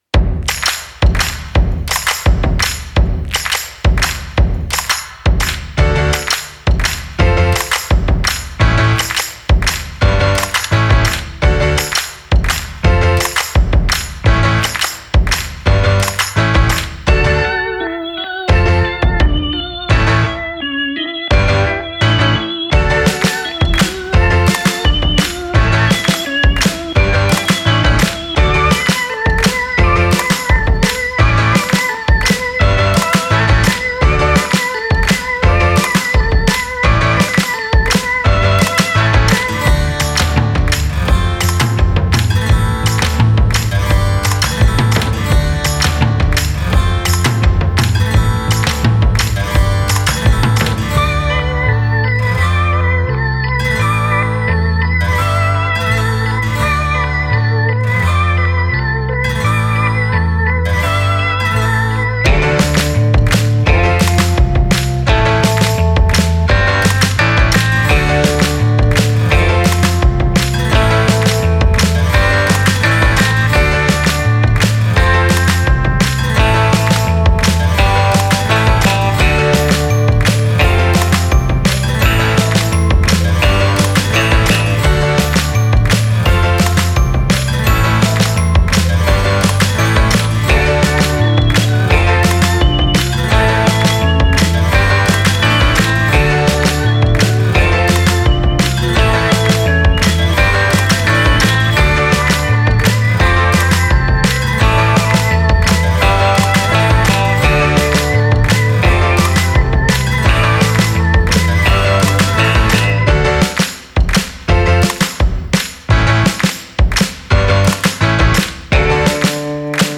это яркий и энергичный трек в жанре поп